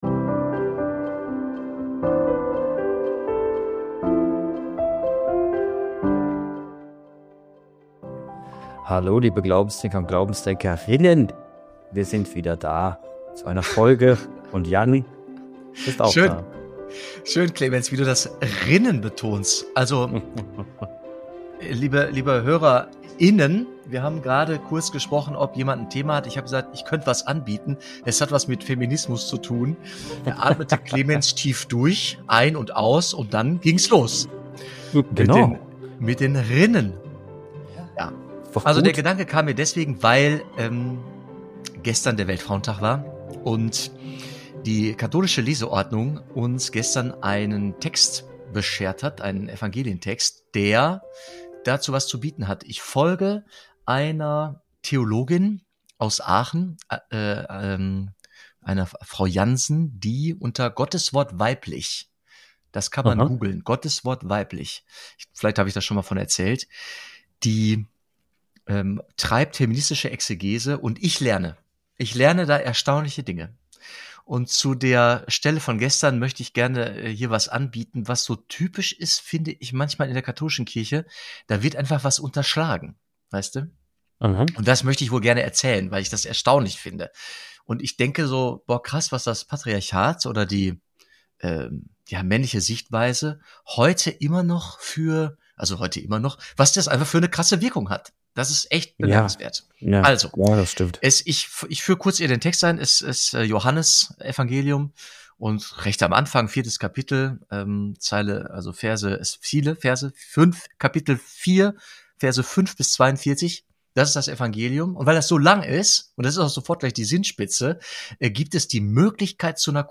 Welche Rolle spielen patriarchale Strukturen in der Überlieferung biblischer Texte? Und warum kann man die Samariterin eigentlich als Apostelin verstehen? Die beiden diskutieren über feministische Theologie, blinde Flecken der kirchlichen Tradition und die zeitlose Frage nach Würde – damals wie heute.